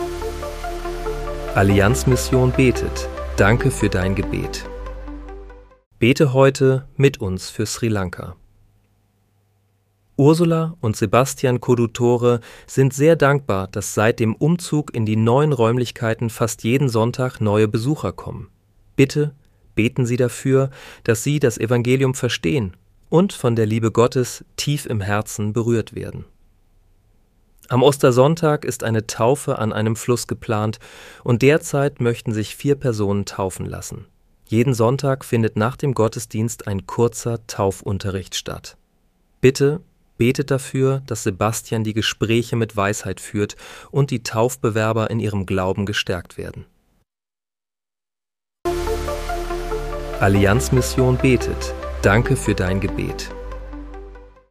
Bete am 30. März 2026 mit uns für Sri Lanka. (KI-generiert mit der